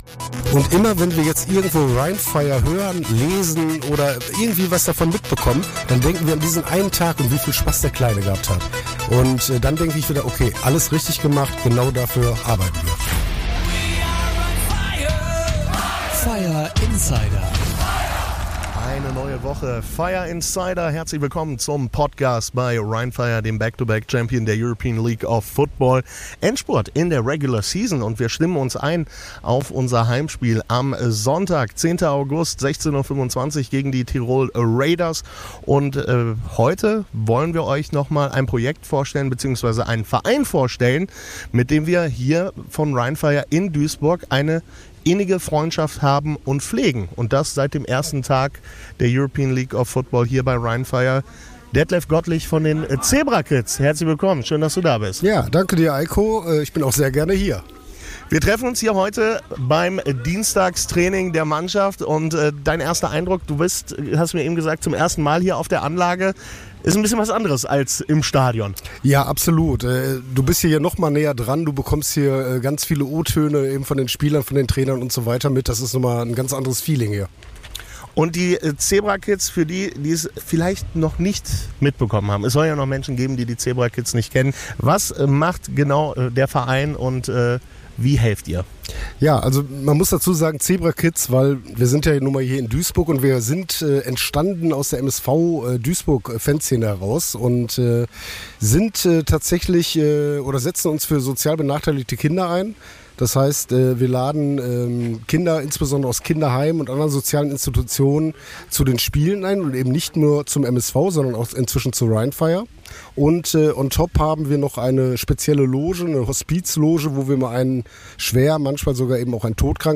- Rückblick auf das Heat-Club-Event am 05.08.2025 im Clayton Hotel Düsseldorf.